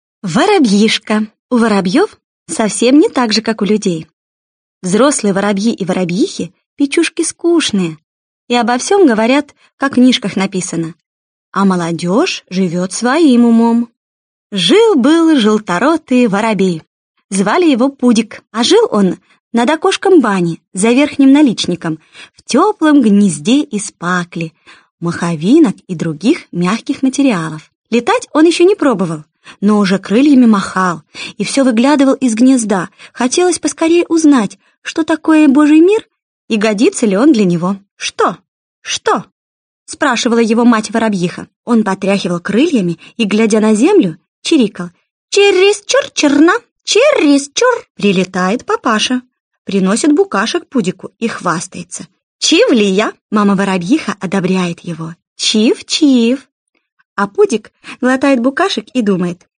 Аудиокнига Сказки русских писателей. Выпуск 1 | Библиотека аудиокниг